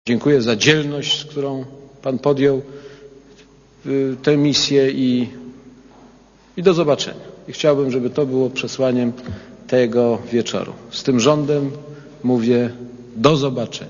Posłuchaj komentarza Aleksandra Kwaśniewskiego